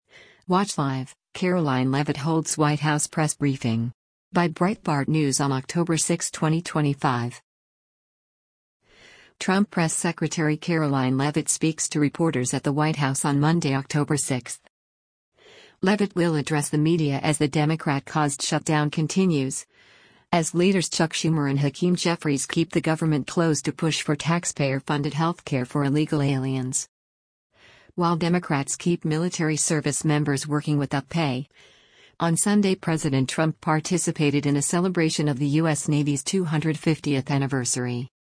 Trump Press Secretary Karoline Leavitt speaks to reporters at the White House on Monday, October 6.